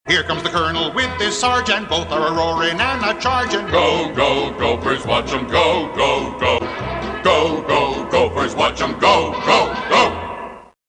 Category: Theme songs